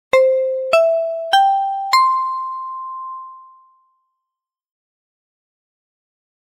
SE（呼び出し）
キンコンカンコン。ピンポンパンポン。